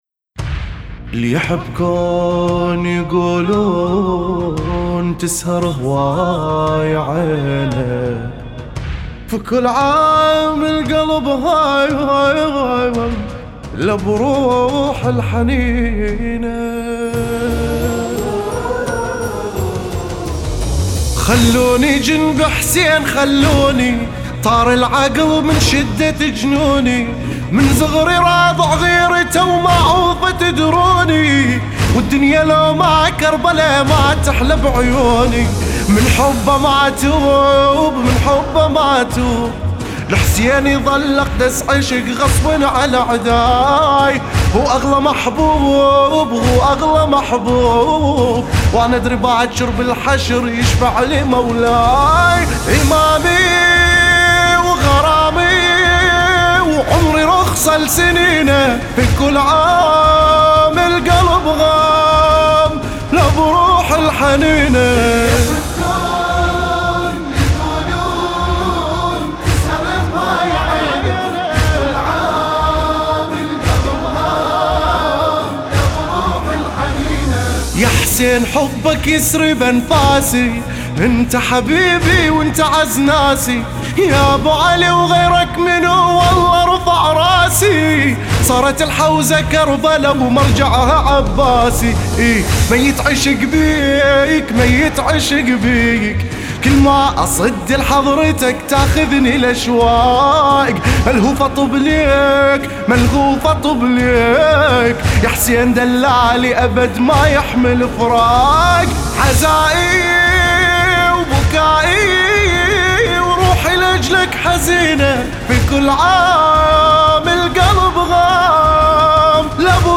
لطمية